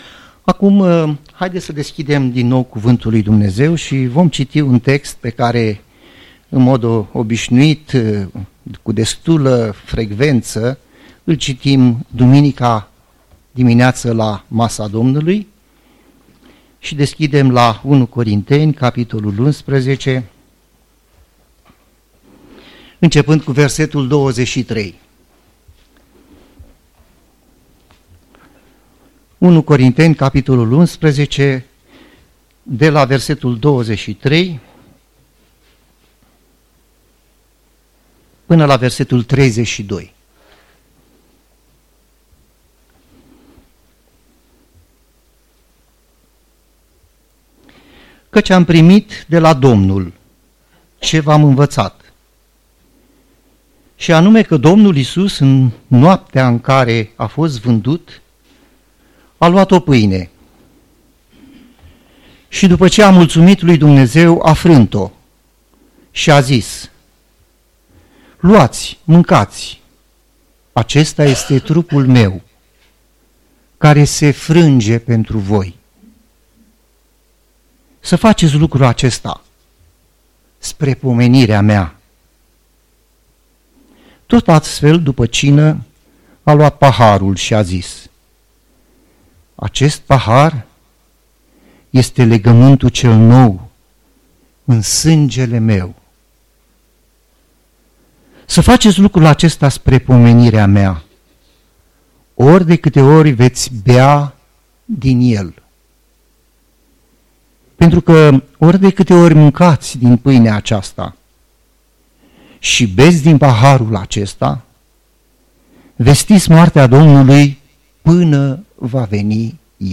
24 dec. 2017, Duminică - ev, 2
dimineața Predică